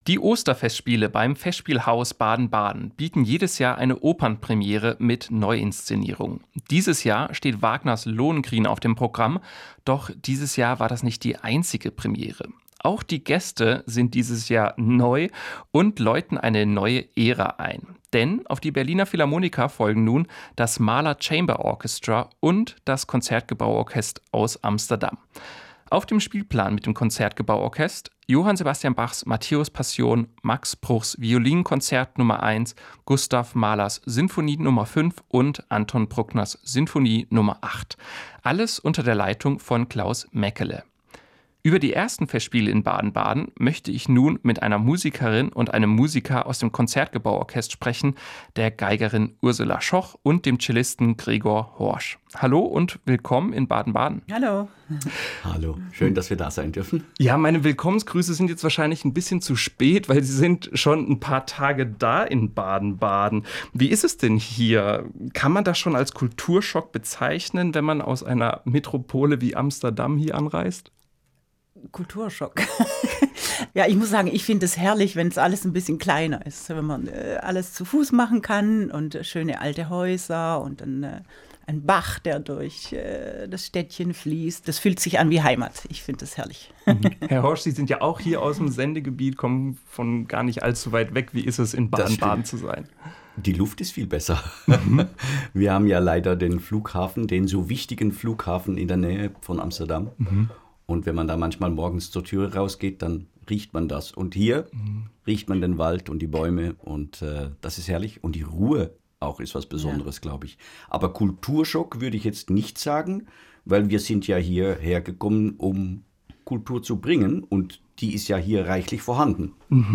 Musikgespräch
Das Interview führte